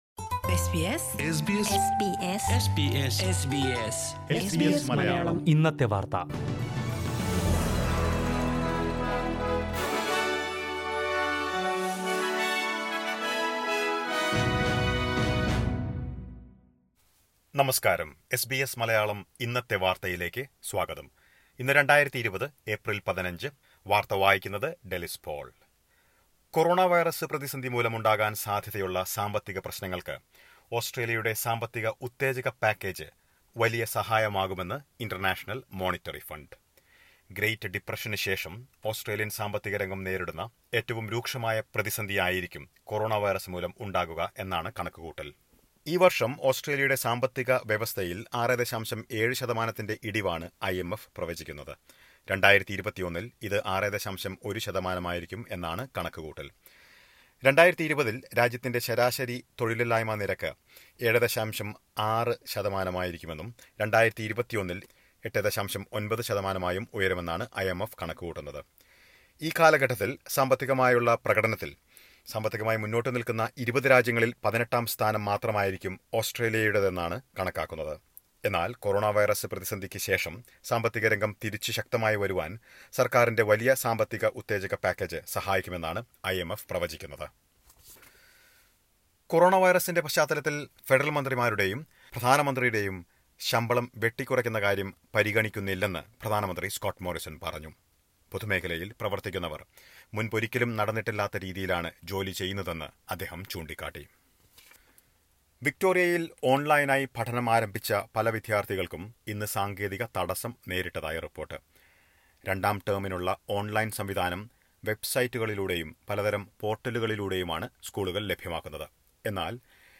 2020 ഏപ്രിൽ 15ലെ ഓസ്ട്രേലിയിയലെ ഏറ്റവും പ്രധാന വാർത്തകൾ കേൾക്കാം.
15444news.mp3